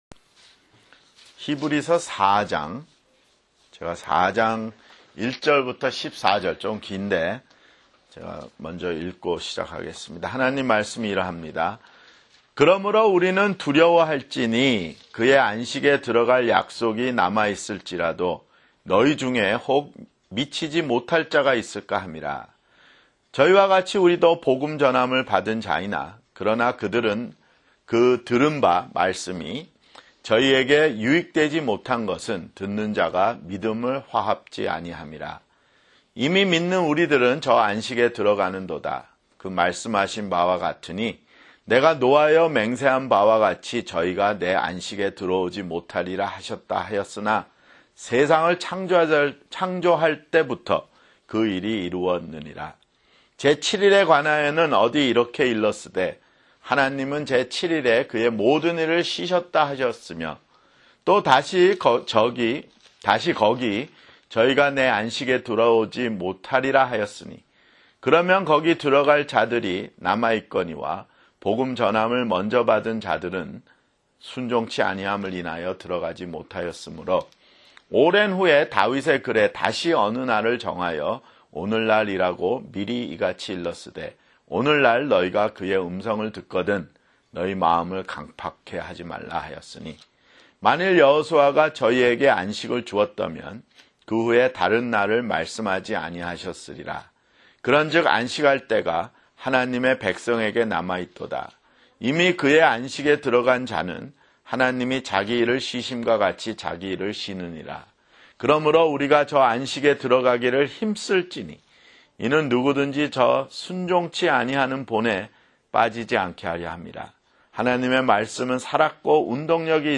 [성경공부] 히브리서 (20)